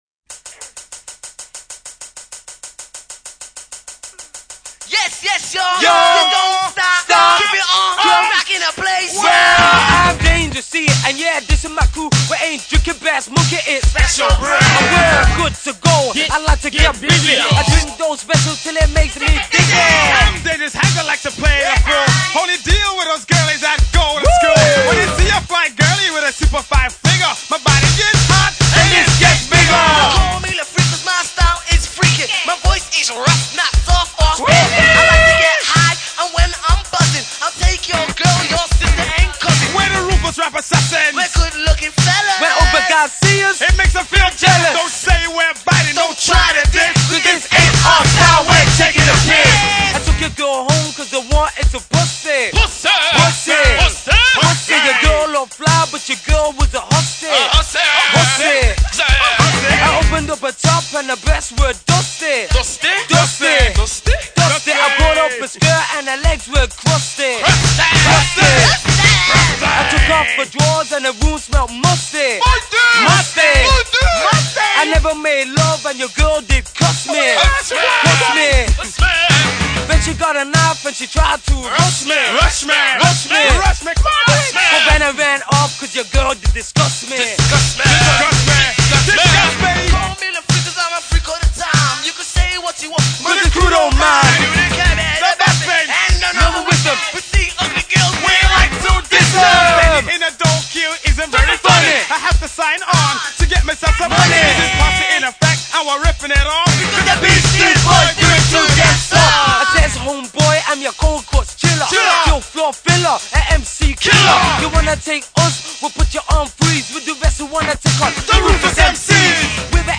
Early Tracks and Demos
Unreleased Early Radio Session